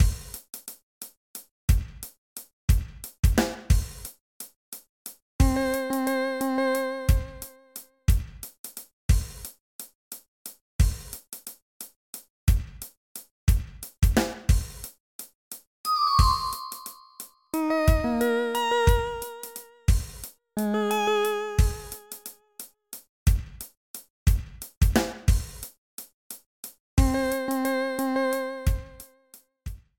Level preview music